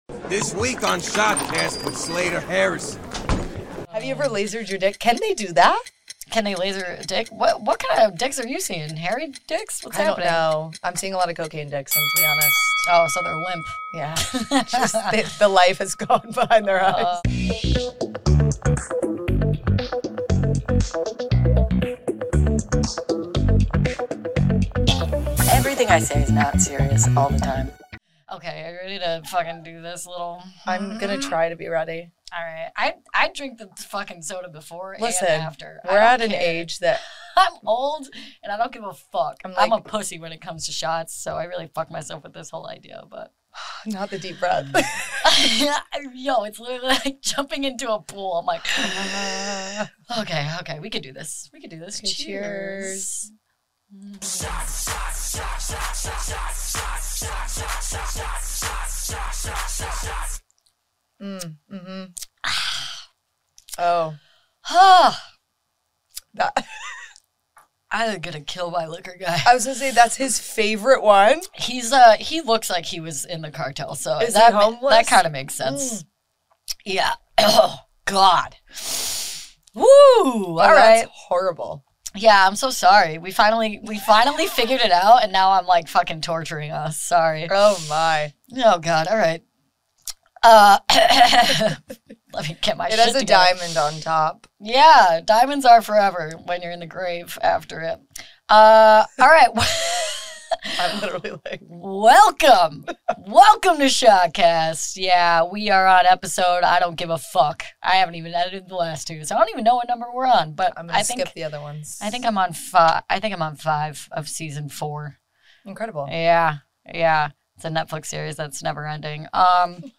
We went from high-energy riffing to a serious soul stroll as we pinballed topics from hot dogs and Sandra Bullock to PowerPoint and escaping Bellevue Hospital.